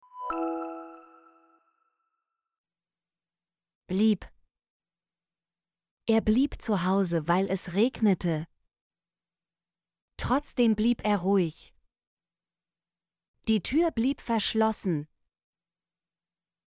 blieb - Example Sentences & Pronunciation, German Frequency List